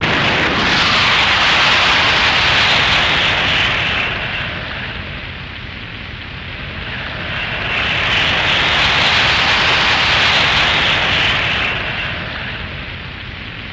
Geyser Blows Initial Blast And Looped